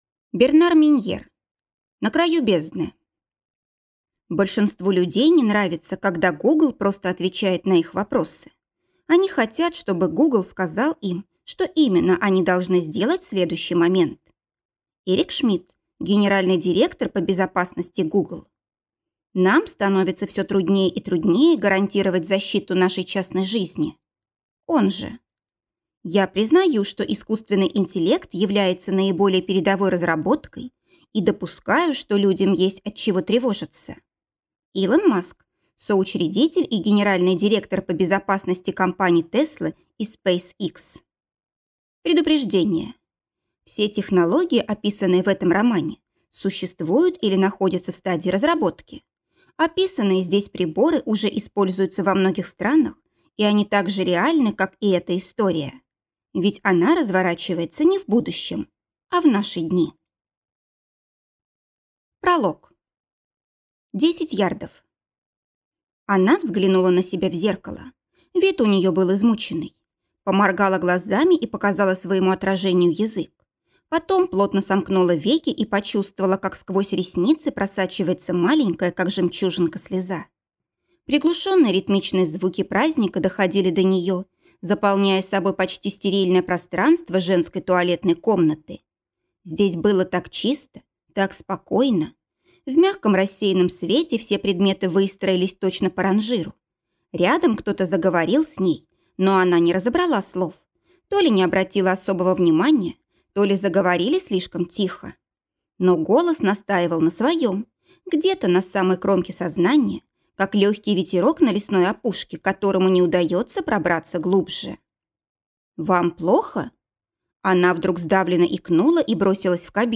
Аудиокнига На краю бездны | Библиотека аудиокниг